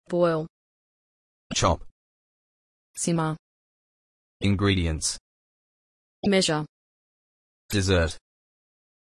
Boil [bɔɪl] (verb) – To heat a liquid until it bubbles and turns to vapor.
Chop [tʃɒp] (verb) – To cut something into small pieces.
Simmer [ˈsɪmər] (verb) – To cook something gently just below boiling point.
Ingredients [ɪnˈɡriːdiənts] (noun) – The items used to make a dish or meal.
Measure [ˈmɛʒər] (verb) – To determine the amount or size of something.
Dessert [dɪˈzɜːrt] (noun) – A sweet dish served at the end of a meal.